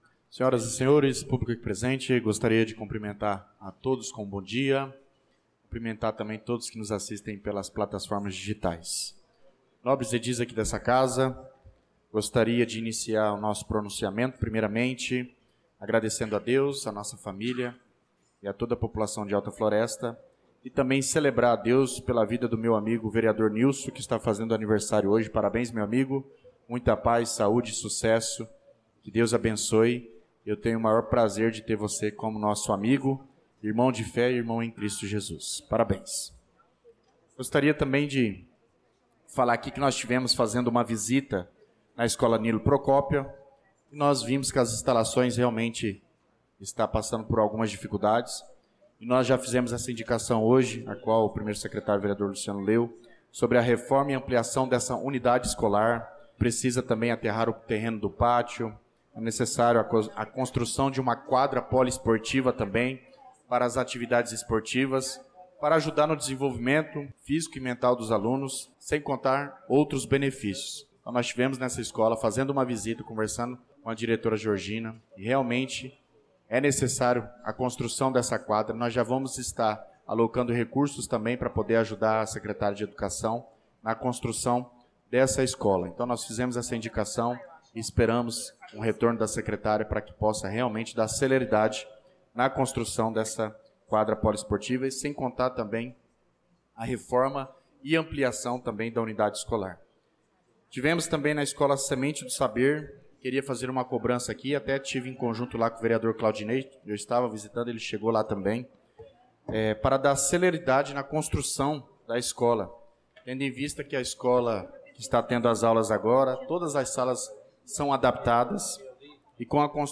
Pronunciamento do vereador Douglas Teixeira na Sessão Ordinária do dia 06/03/2025